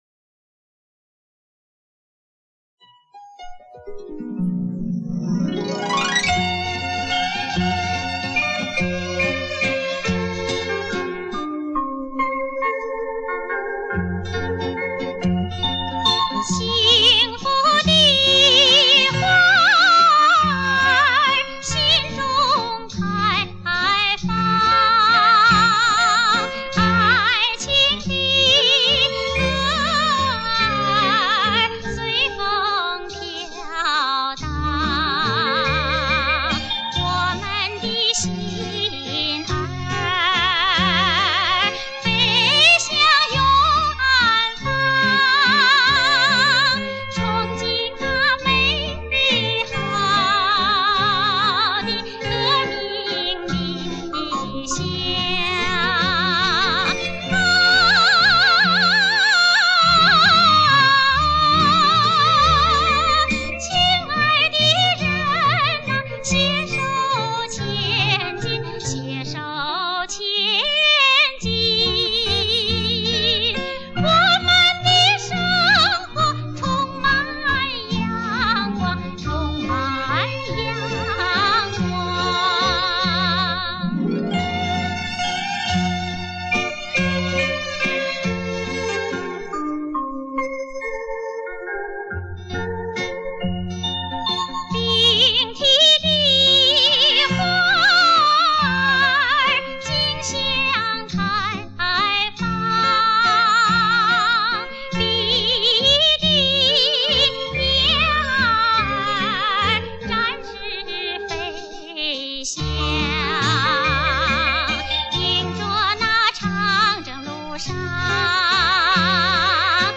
是较早的用电声音乐伴奏的电影歌曲。歌曲抒情、跃动、明快，反映出人们对新生活的歌颂、对明天的憧憬。
LP 原版录音 单声道 [192K MP3]